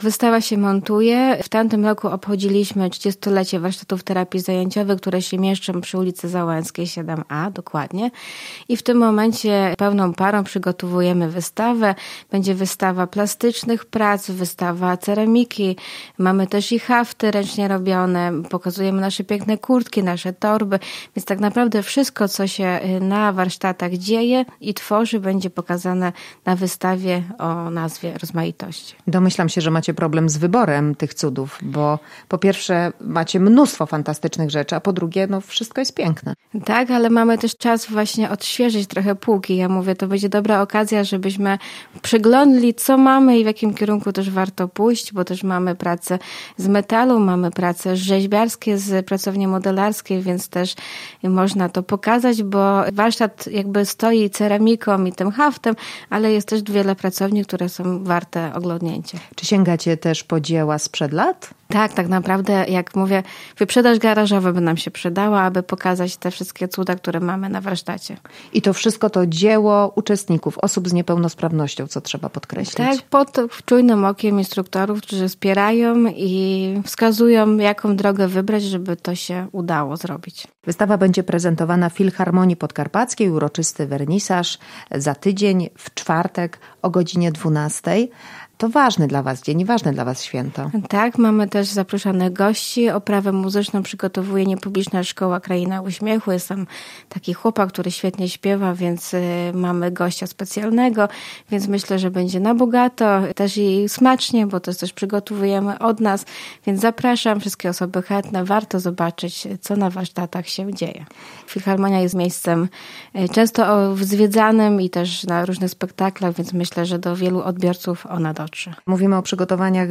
audycja